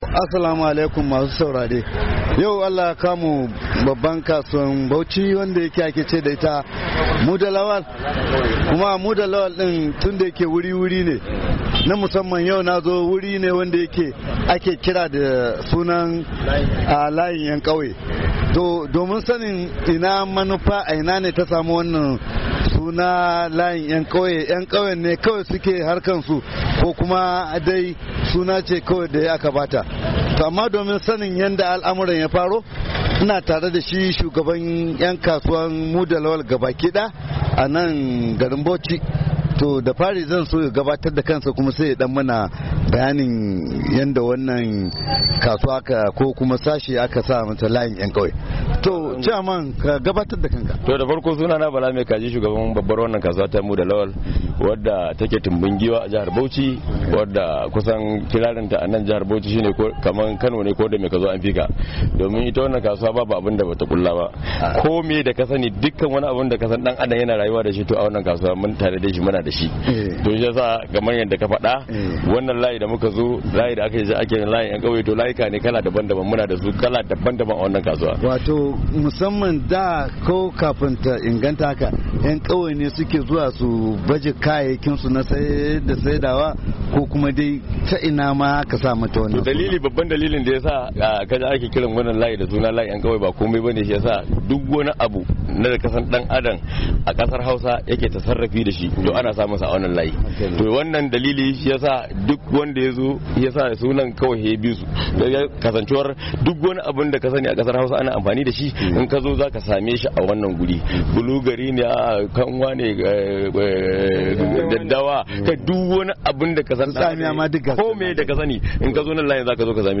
A wannan makon shirin kasuwa ya kai ziyara layin 'yan kauye a kasuwar Muda Lawal da ke garin Bauchi a Najeriya.